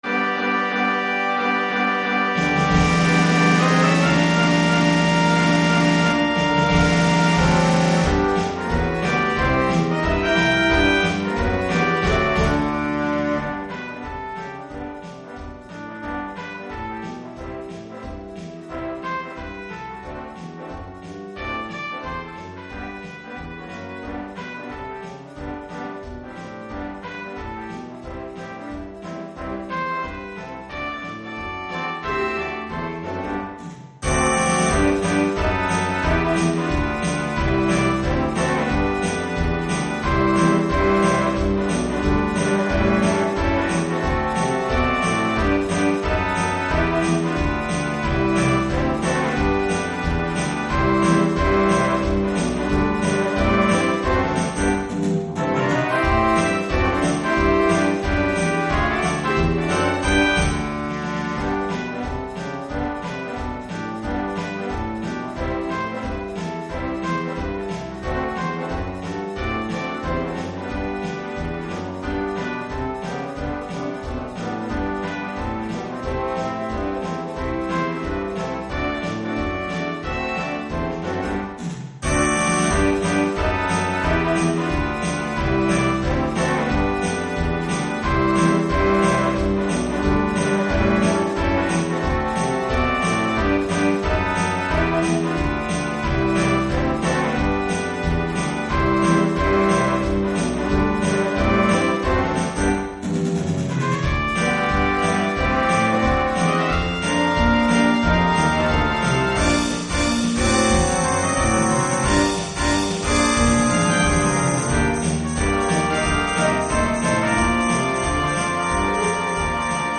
Piano, Guitar, Bass Guitar, Tambourine & Drum Set optional.
5-Part Ensemble